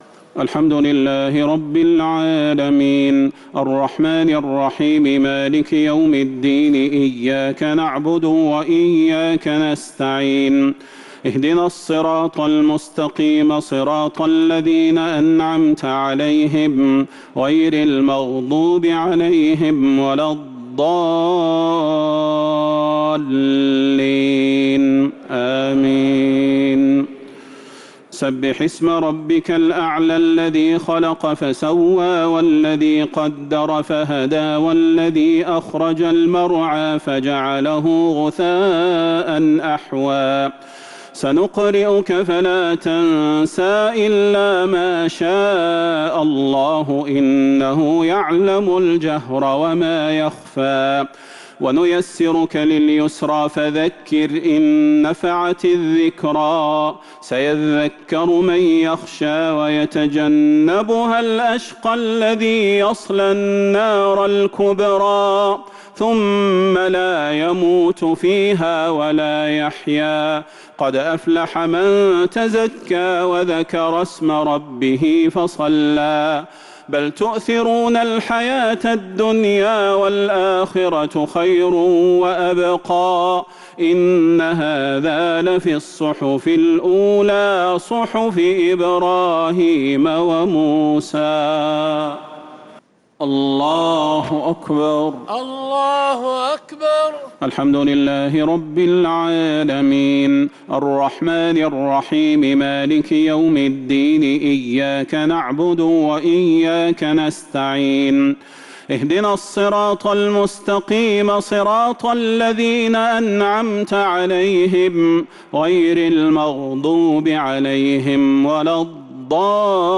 الشفع والوتر ليلة 18 رمضان 1447هـ | Witr 18th night Ramadan 1447H > تراويح الحرم النبوي عام 1447 🕌 > التراويح - تلاوات الحرمين